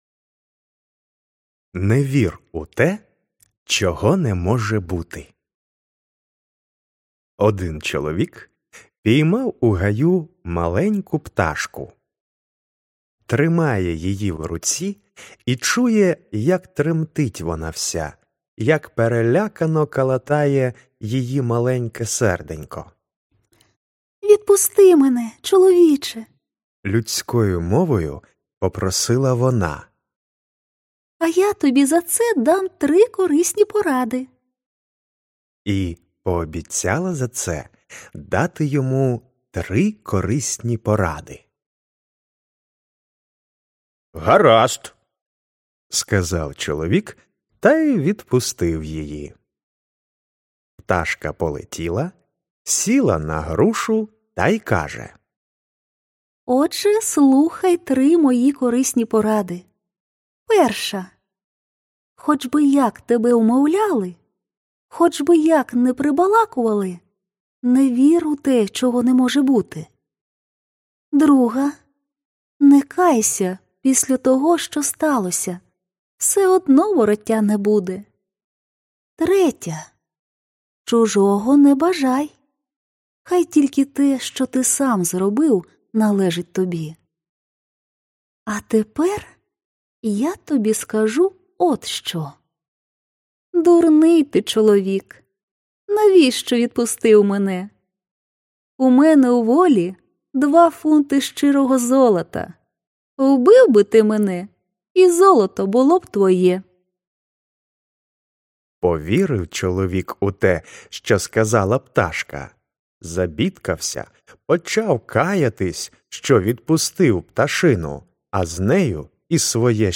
Аудіоказка Не вір у те, чого не може бути
• Народні
Жанр: Пригоди / Навчання / Літературна казка